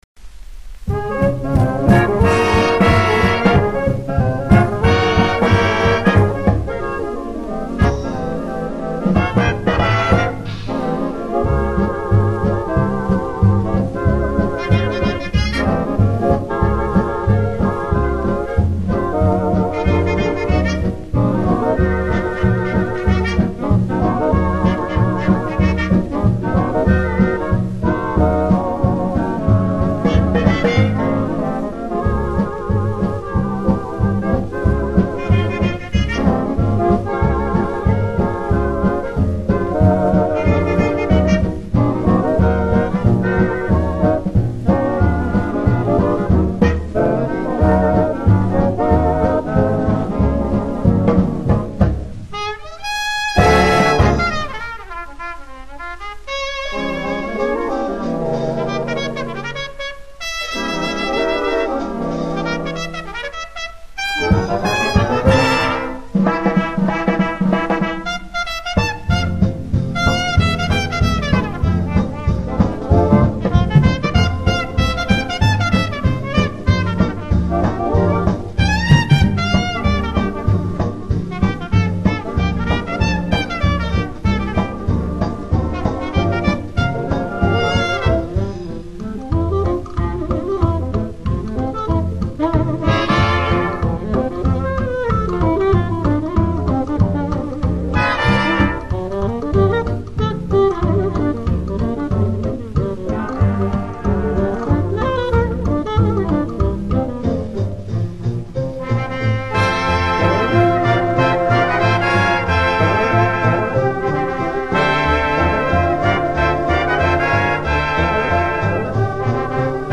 как одно из свинговых произведений